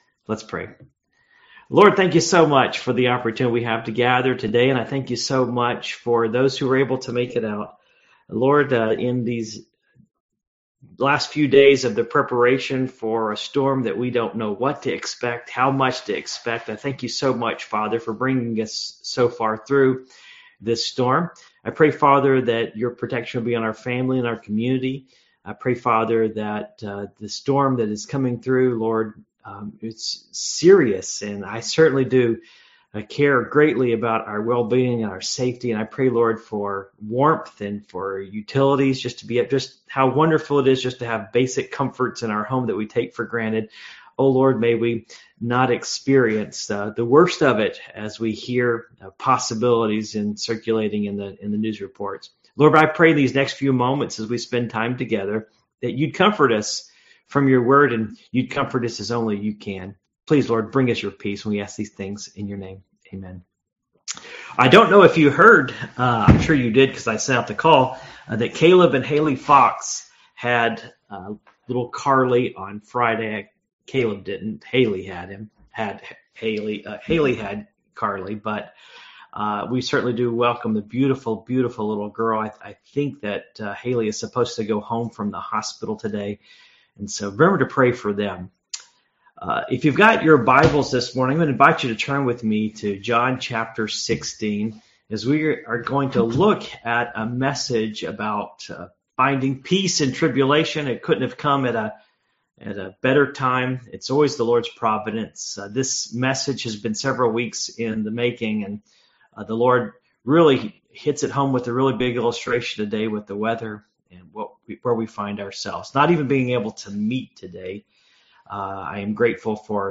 Passage: John 16:25-33 Service Type: Morning Worship